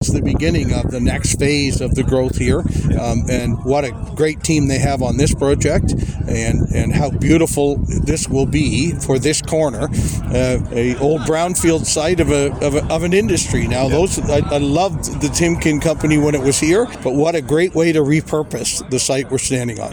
That was the observation of Mayor Joe Preston on Wednesday morning for the groundbreaking ceremony at the 14-storey Highlands Tower 1 apartment building .